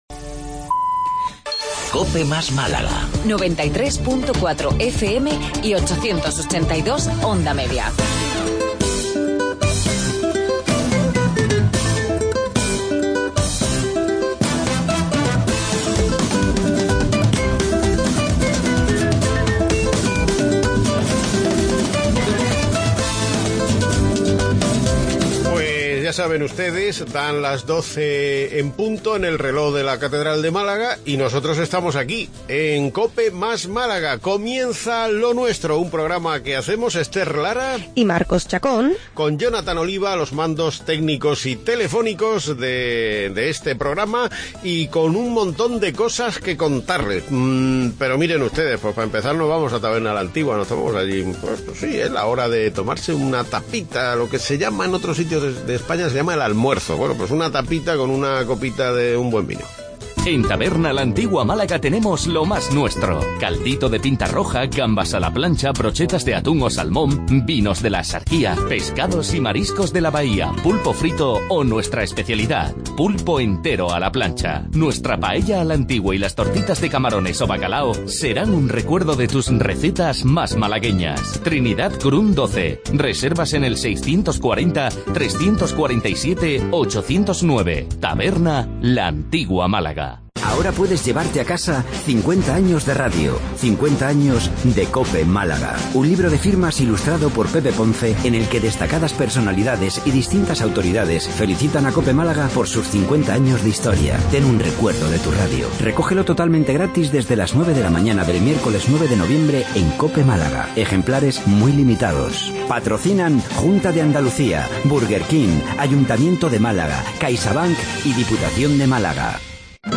Magazine diario